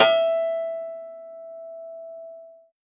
53v-pno08-E3.aif